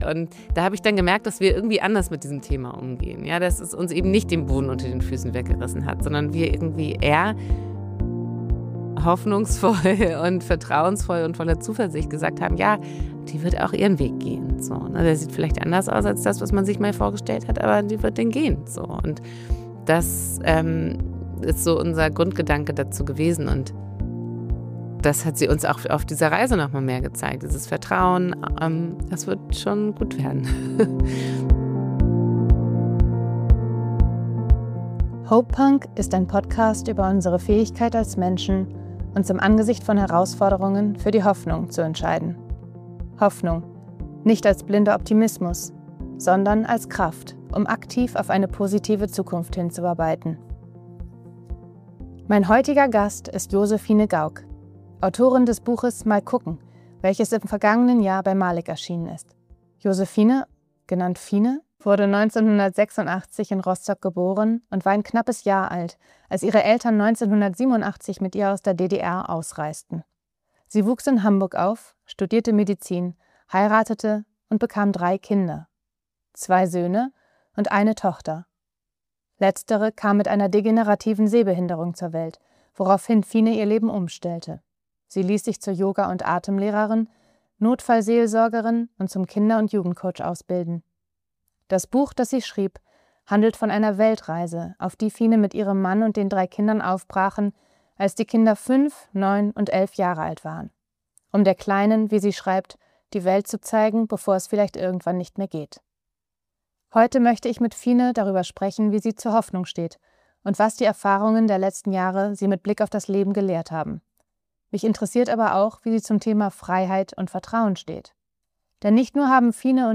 Ein Gespräch über die Kraft des Perspektivwechsels.